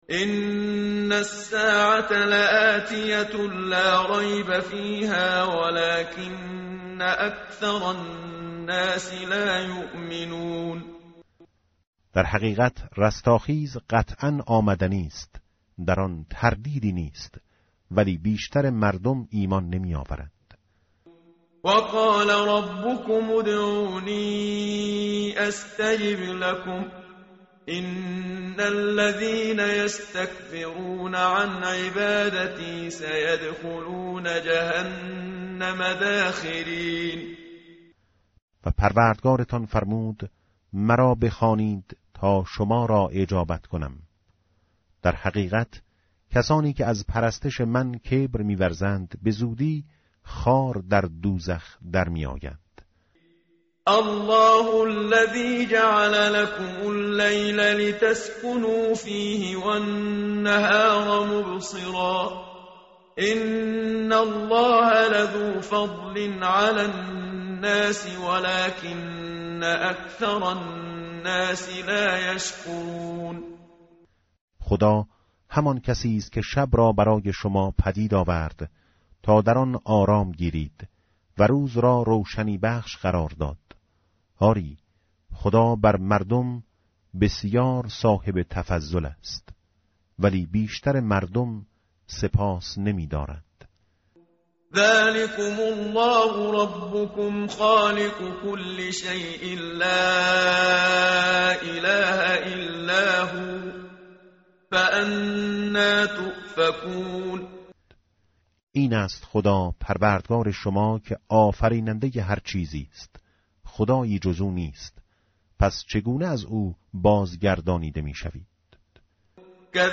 متن قرآن همراه باتلاوت قرآن و ترجمه
tartil_menshavi va tarjome_Page_474.mp3